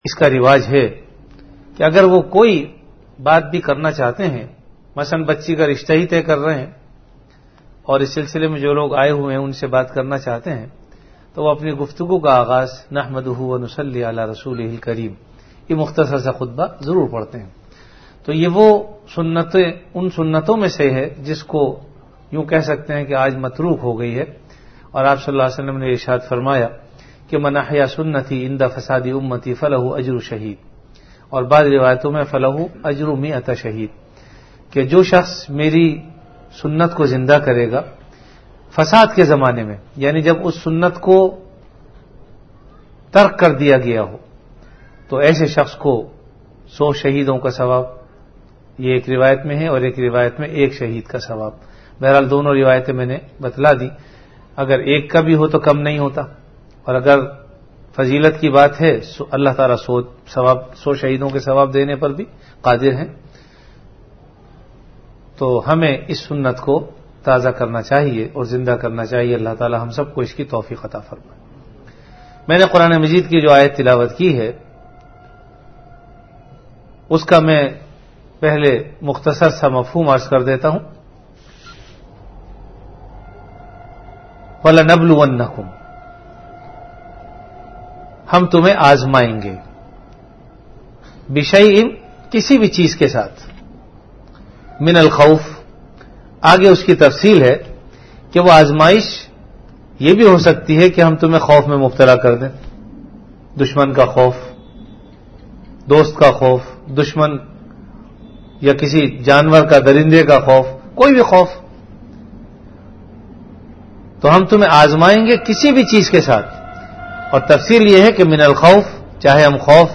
Category Bayanat
Event / Time After Isha Prayer